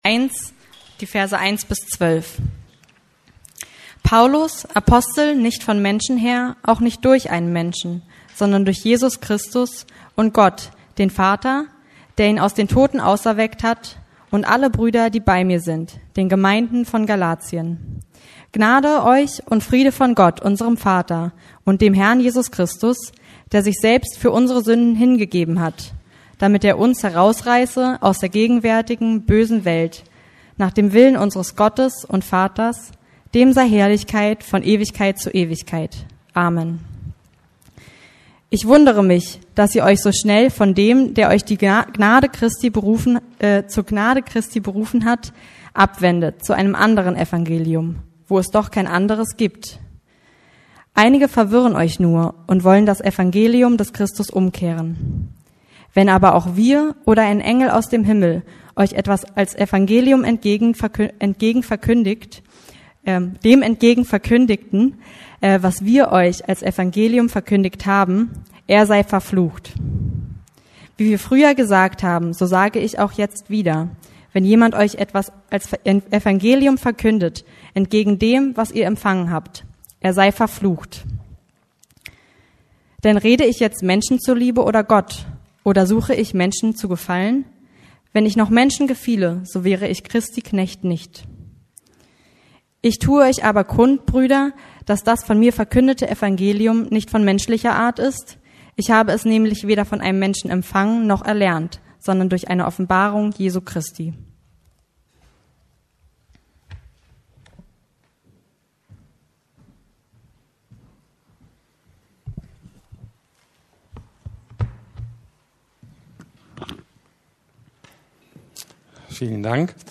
Das Evangelium ~ Predigten der LUKAS GEMEINDE Podcast